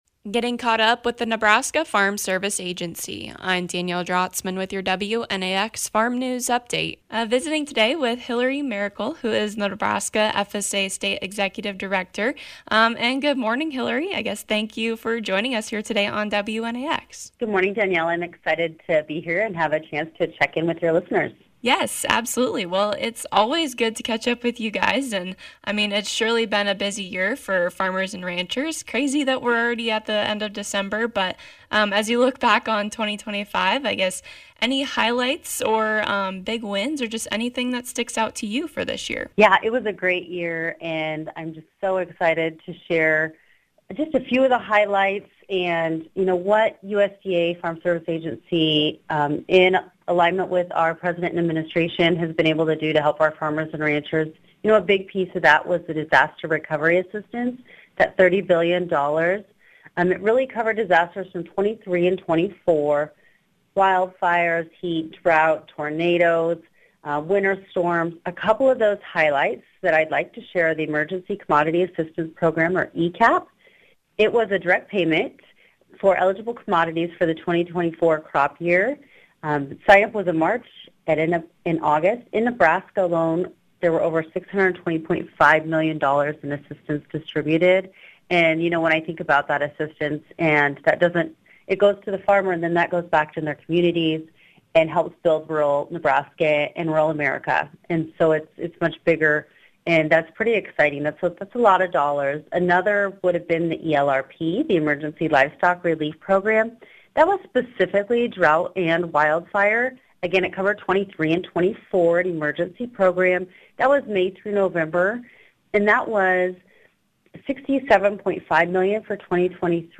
Highlights from 2025 and what to look forward to in 2026. Hear from Hilary Maricle, NE FSA State Executive Director.